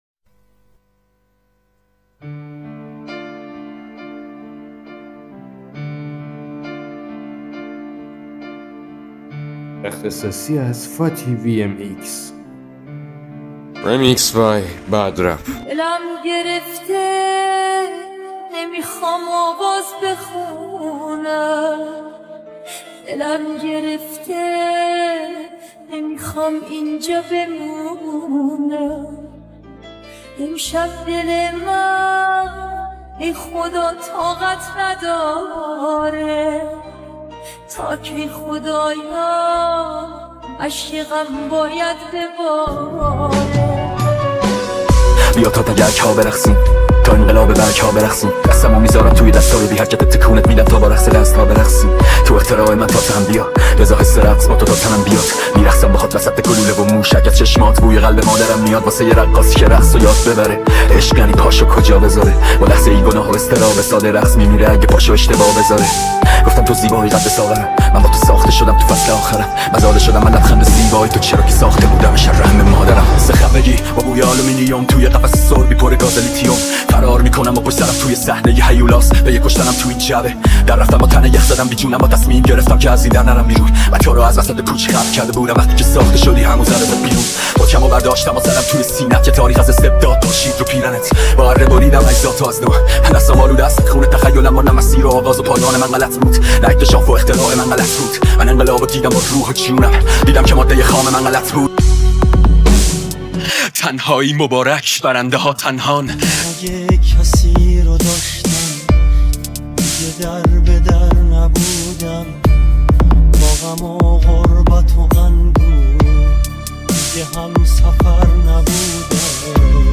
ریمیکس رپی
Remix Rapi